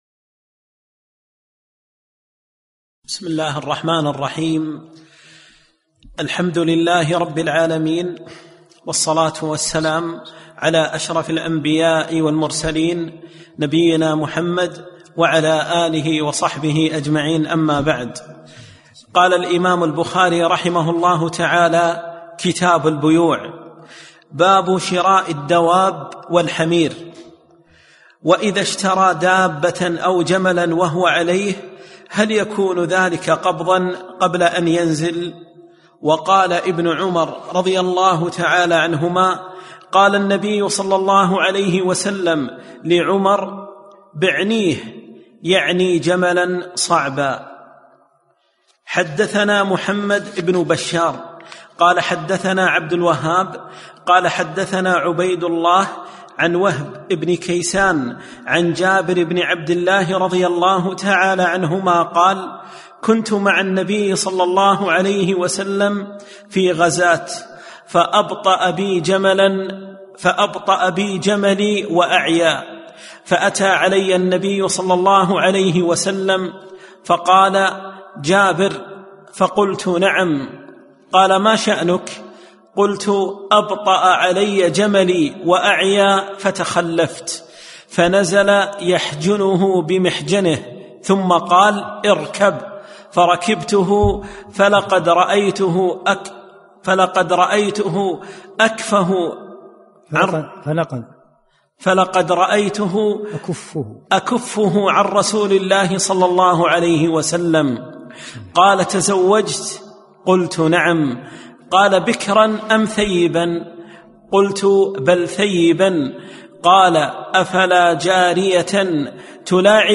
7- الدرس السابع